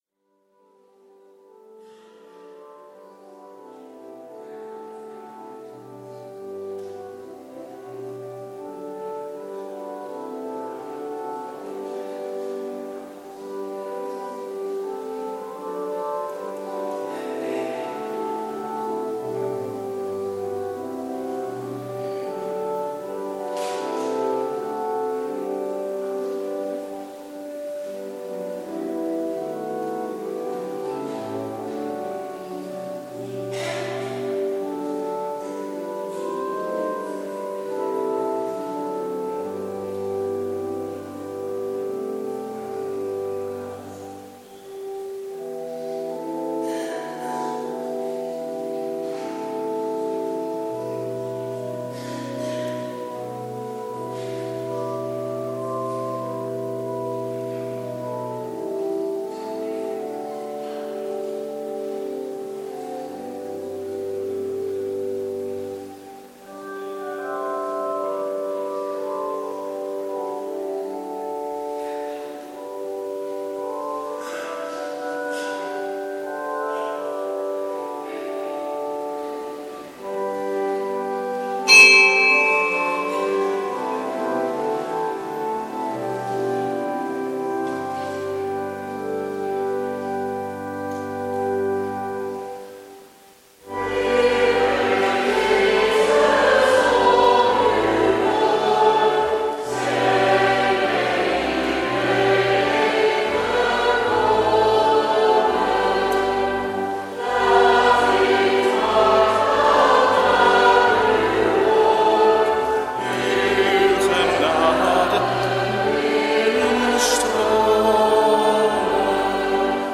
Eucharistieviering beluisteren (MP3)